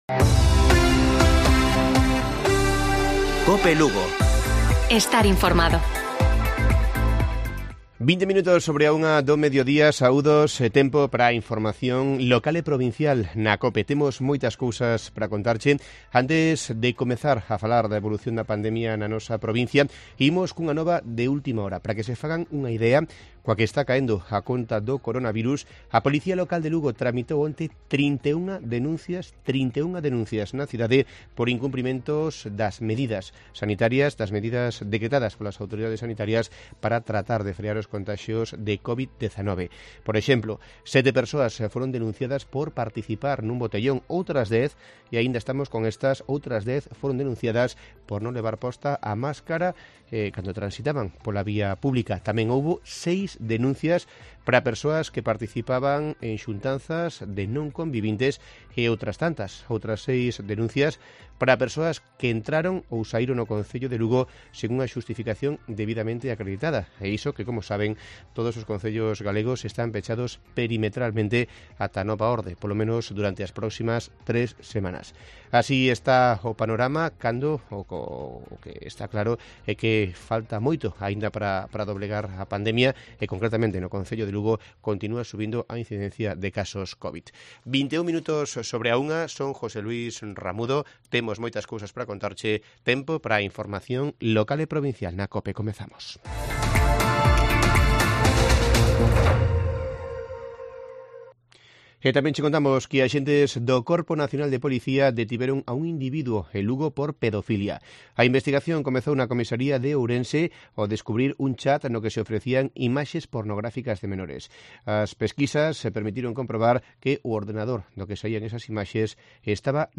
Informativo Provincial de Cope Lugo. 29 de enero. 13:20 horas